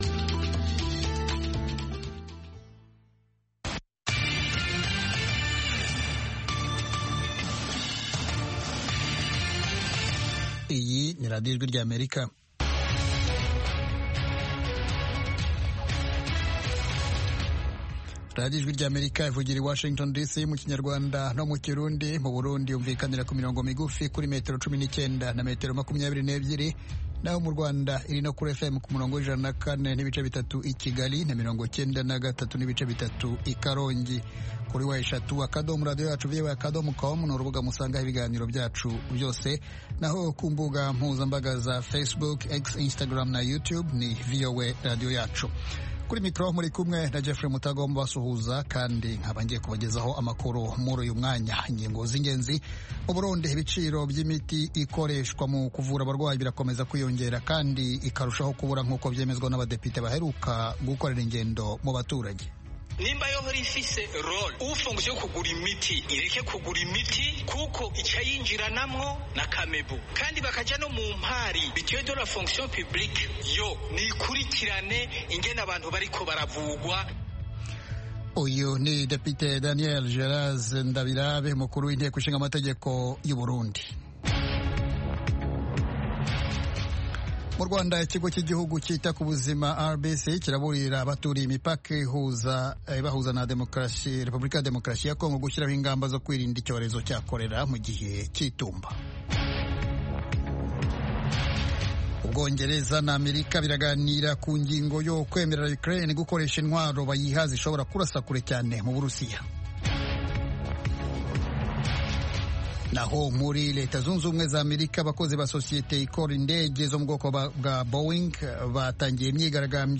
Ejo ni ikiganiro cy'iminota 30 gitegurwa n'urubyiruko rwo mu Rwanda, kibanda ku bibazo binyuranye ruhura na byo. Ibyo birimo kwihangira imirimo, guteza imbere umuco wo kuganiro mu cyubahiro, no gushimangira ubumwe n'ubwiyunge mu karere k'ibiyaga bigari by'Afurika.